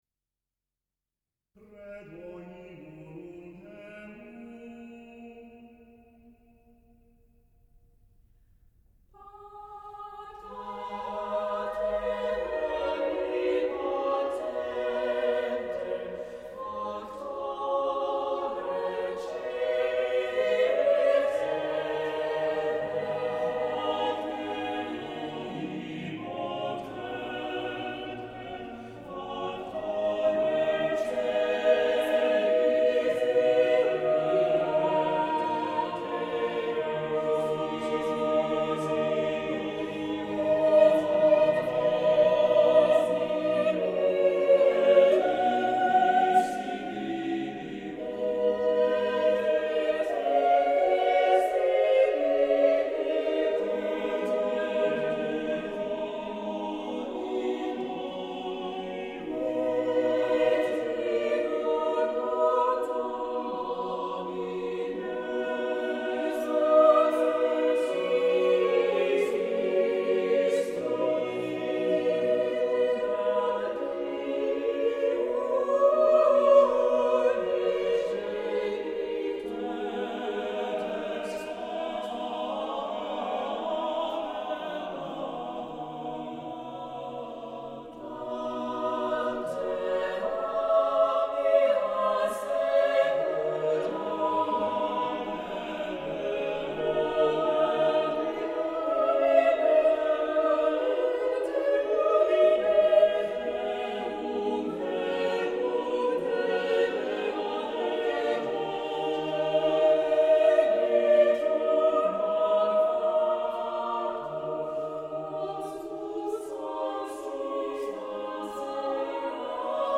Vocal Ensemble